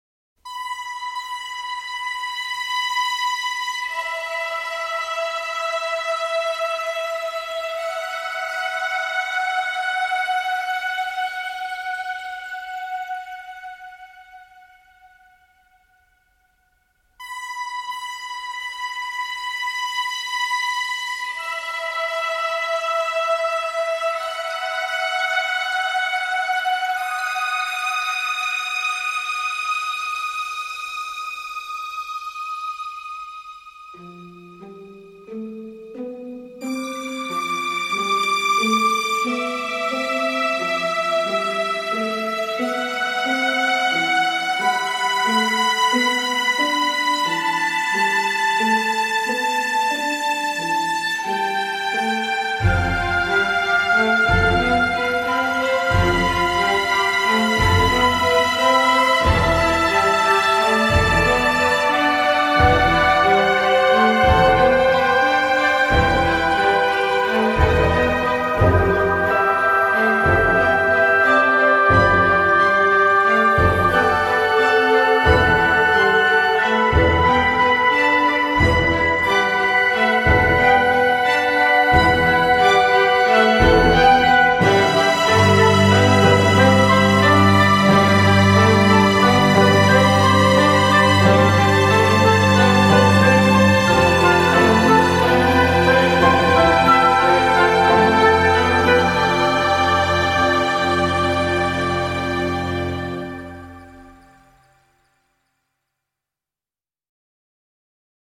C’est frais et léger, ça se consomme sans faim !